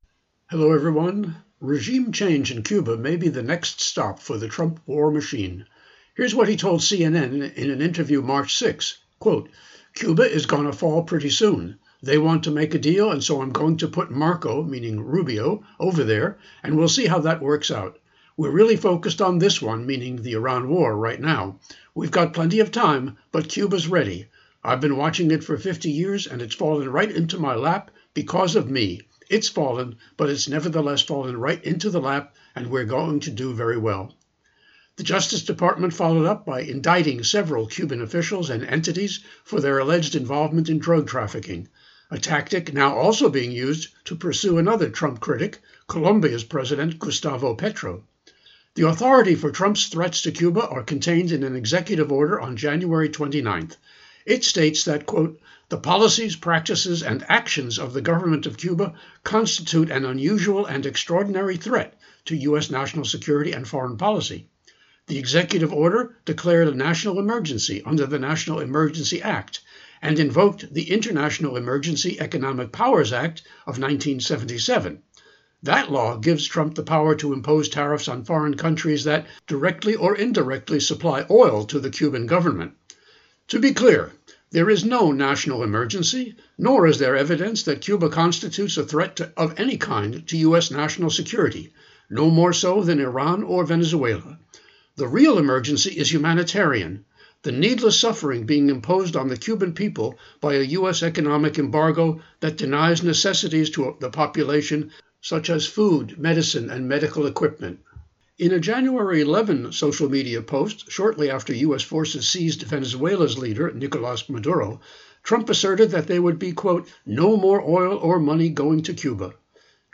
Genre(s): Public Affairs